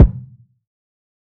TC2 Kicks1.wav